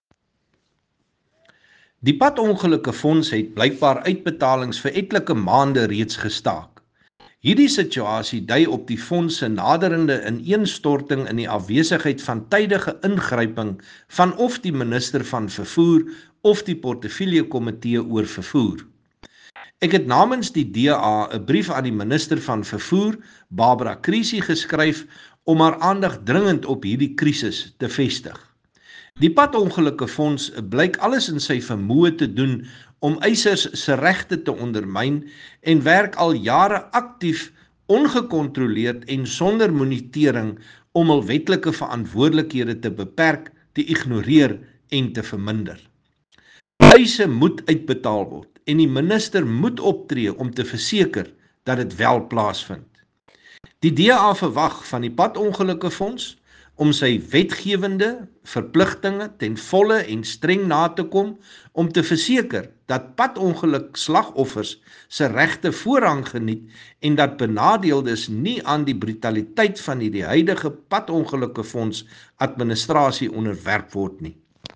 Issued by Dr Chris Hunsinger MP – DA Spokesperson on Transport